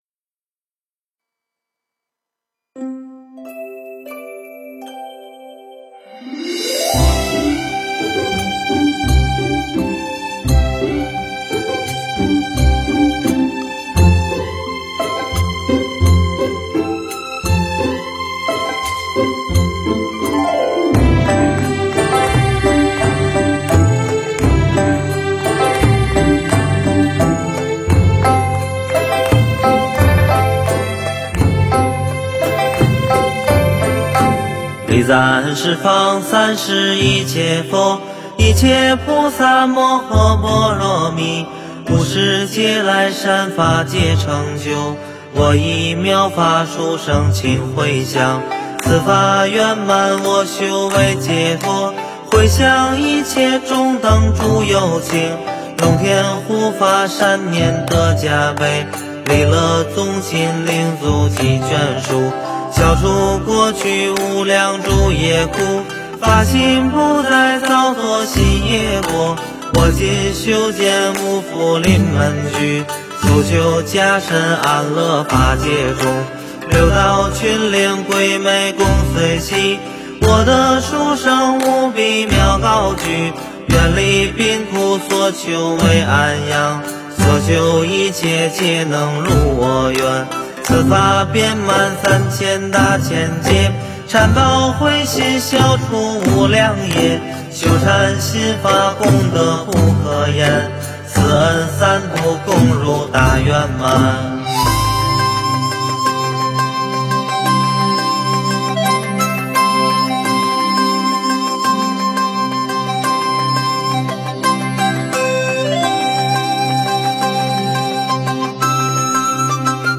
诵经
佛音 诵经 佛教音乐 返回列表 上一篇： 供养请 下一篇： 祈愿颂 相关文章 永恒的低语--《坐禅曲》 永恒的低语--《坐禅曲》...